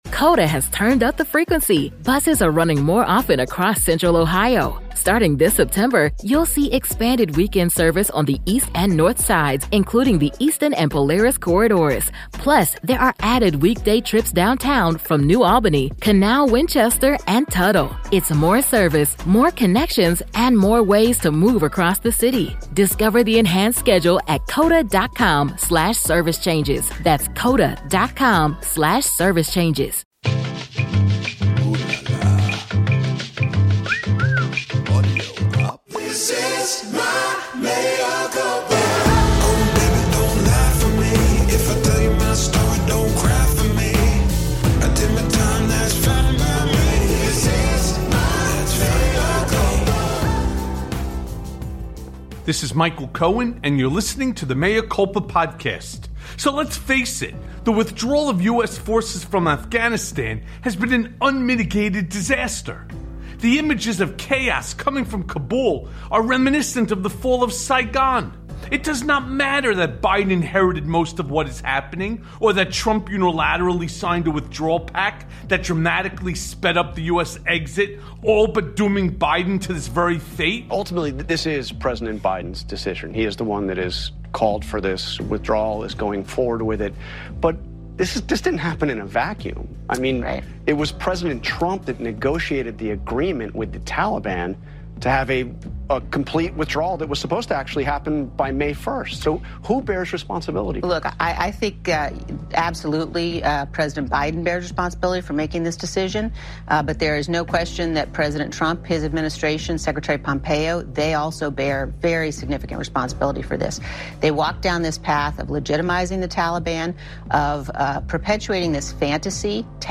Mass Covid Death Comes to MAGA Country + A Conversation With Kurt Eichenwald